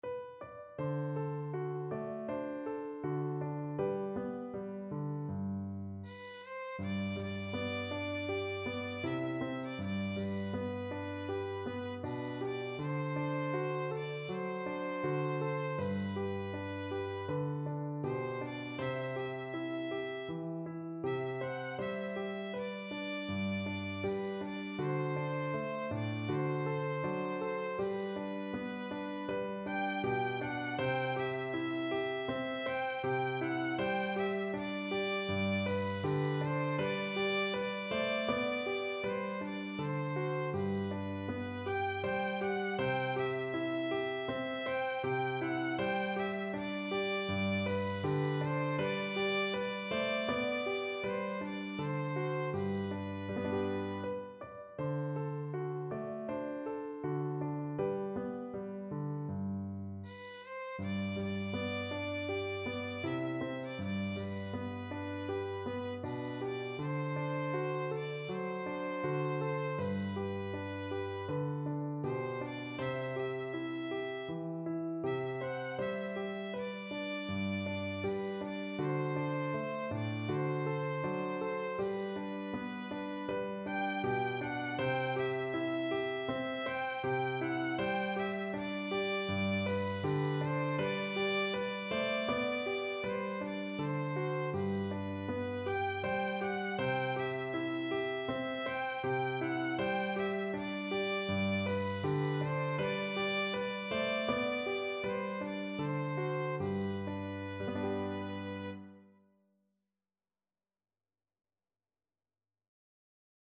4/4 (View more 4/4 Music)
Andante
Classical (View more Classical Violin Music)